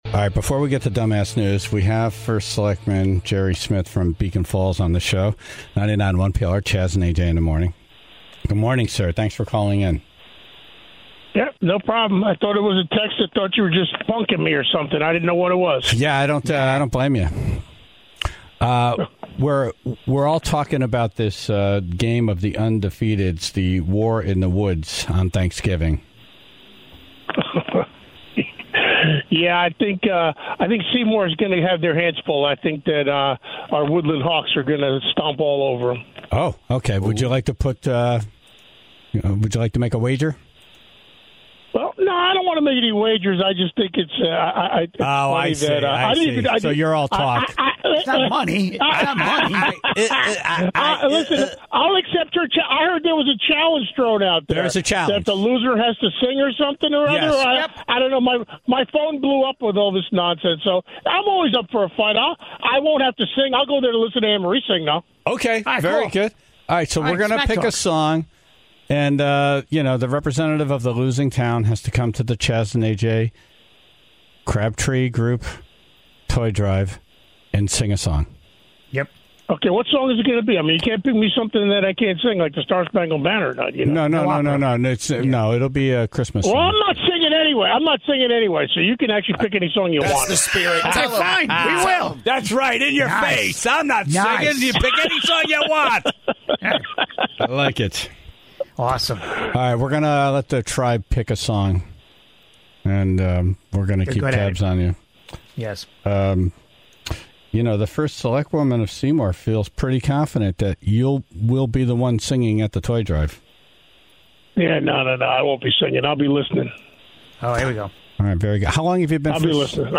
and took calls from the Tribe about their bird strike stories.